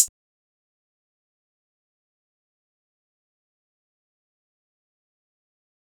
Hihat (Random).wav